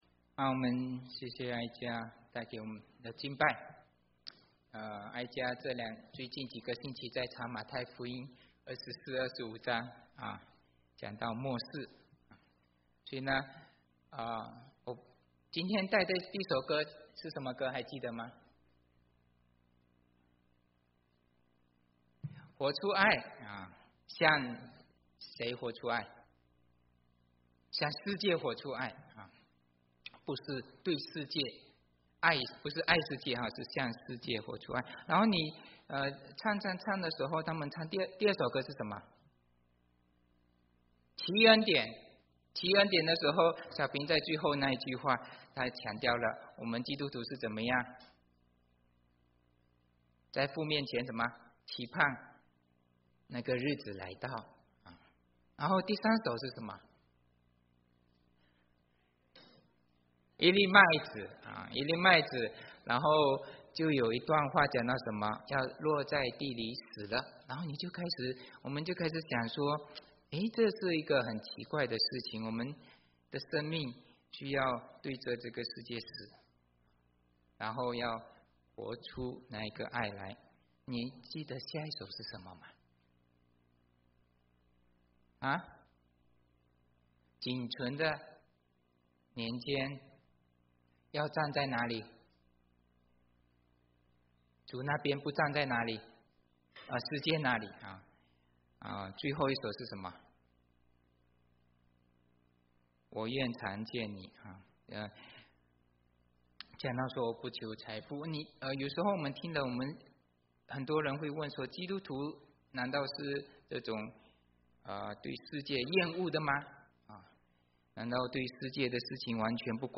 Mandarin Sermons Home / Mandarin Sermons